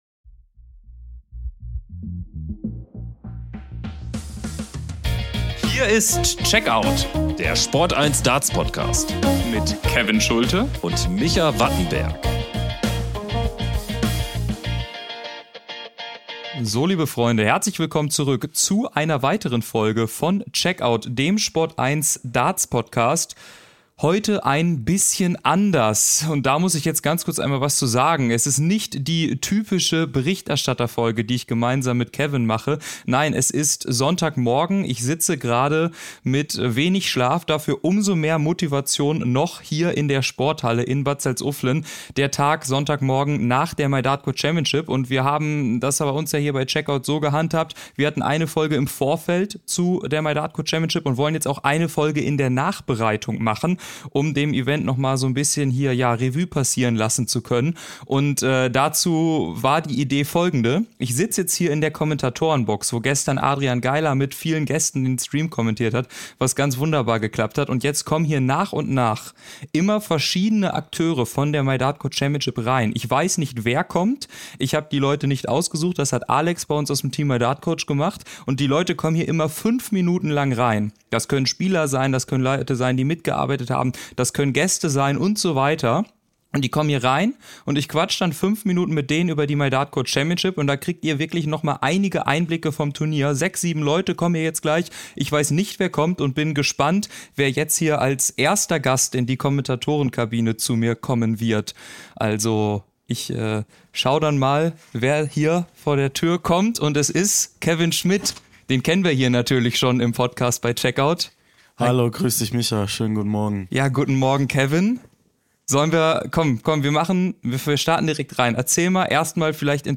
Sonderfolge mit Interview-Sixpack: So lief die MyDartCoach Championship 2024 ~ Darts Podcast